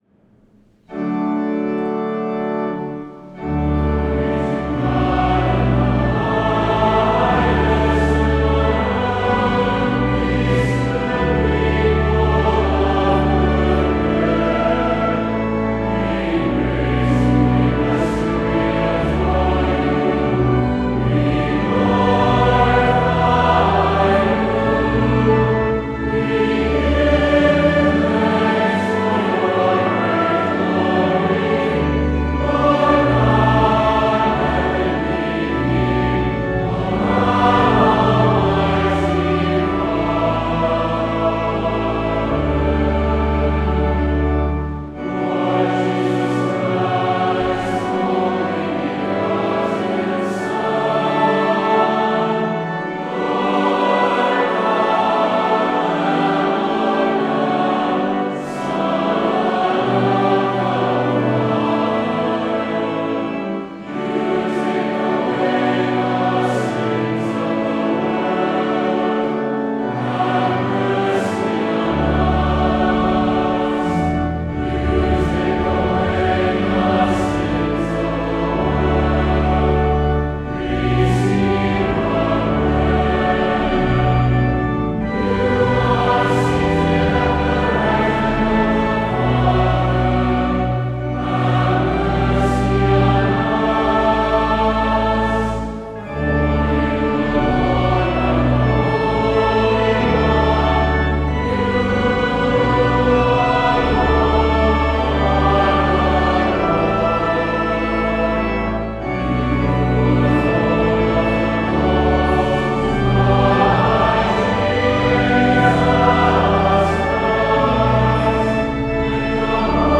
A recording of our current Mass Ordinary Setting can be found below!